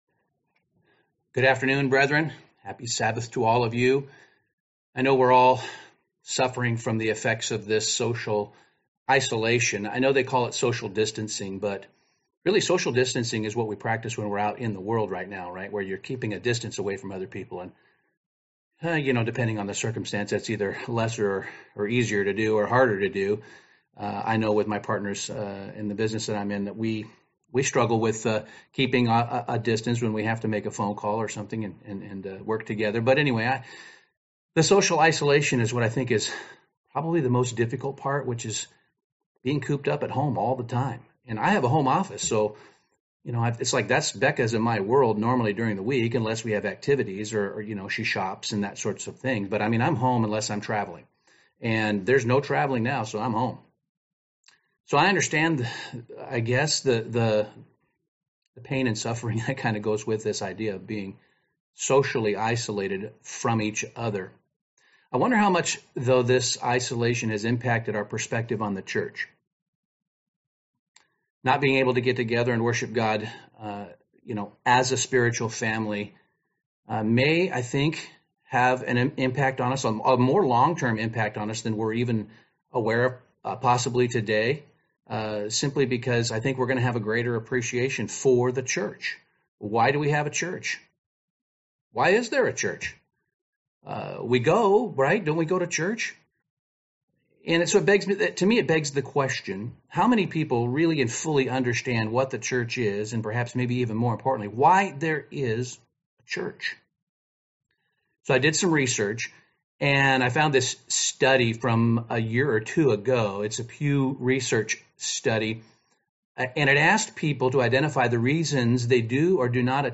This sermon examines these questions to define what the Church is, why there is a Church, and why you've been called to be a part of it.